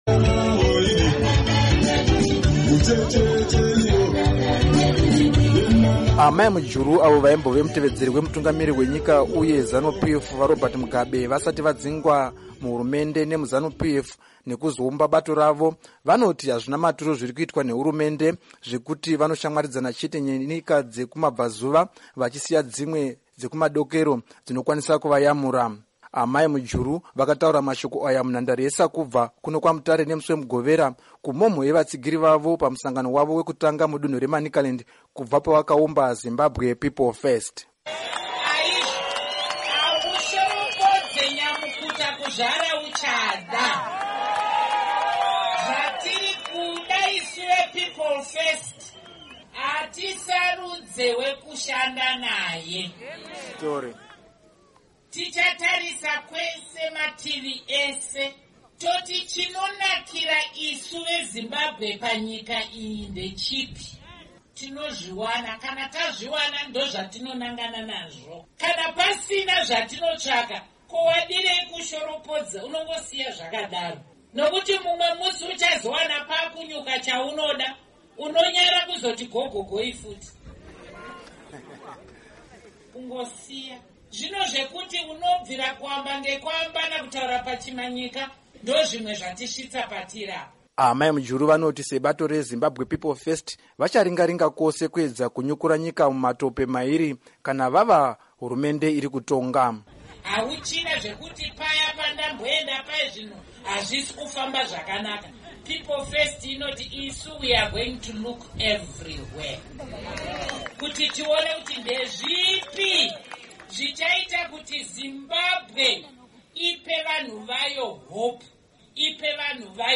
Amai Mujuru vakataura mashoko aya munhandare yeSakubva kuno kwaMutare nemusi weMugovera kumhomho yevatsigiri vavo pamusangano wavo wekutanga mudunhu reManicaland kubva pavakaumba ZimPF.